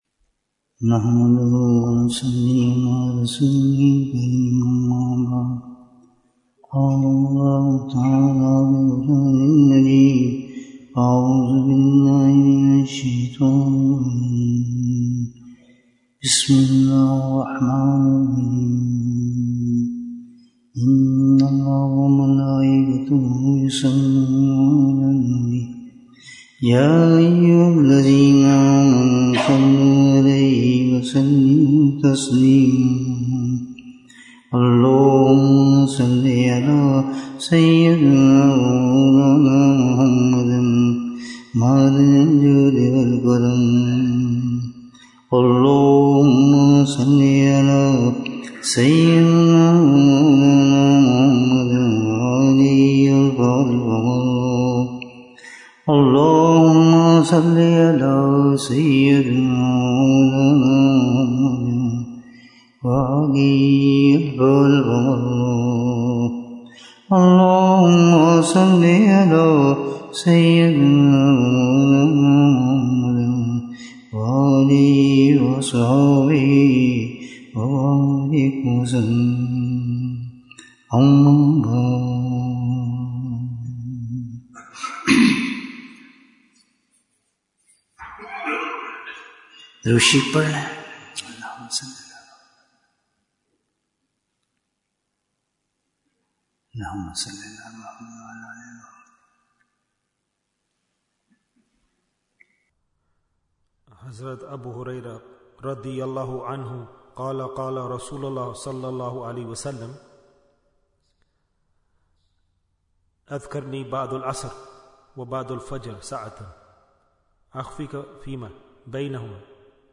Jewels of Ramadhan 2025 - Episode 16 Bayan, 33 minutes13th March, 2025